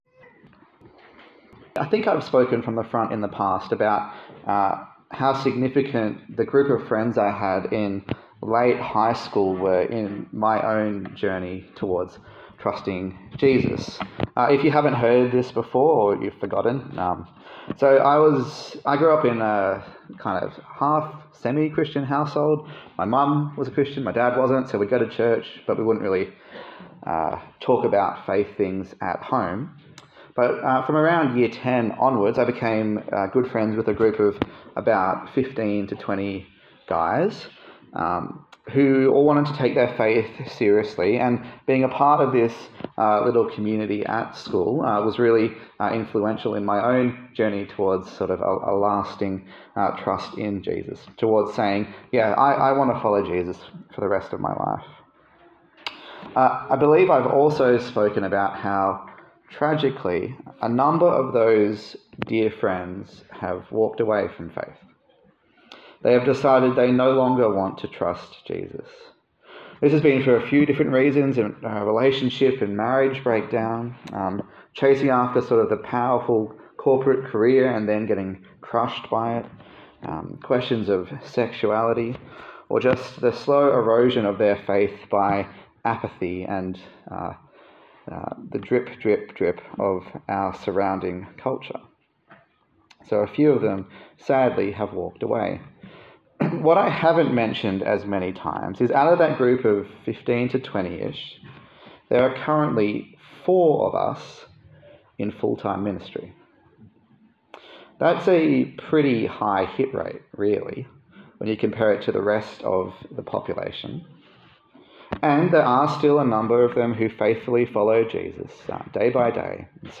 A sermon in the series on the Letter to the Hebrews
Service Type: Sunday Service